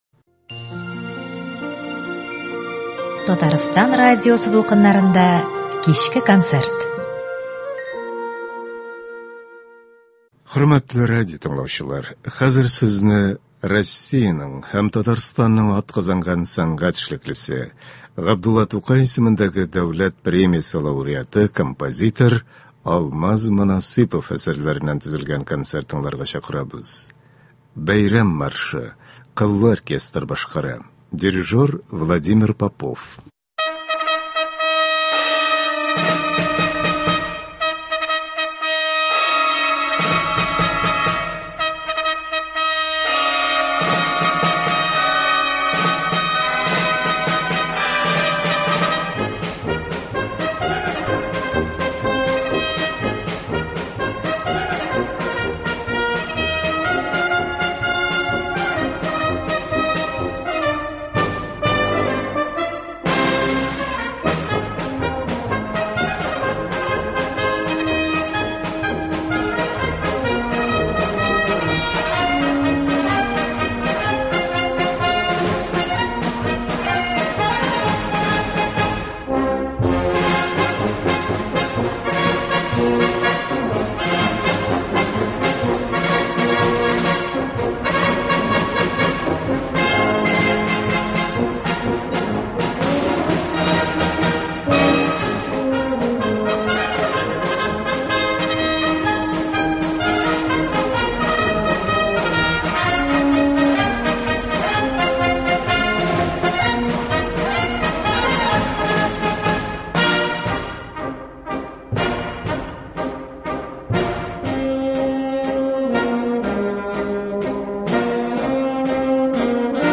Концерт (25.06.24)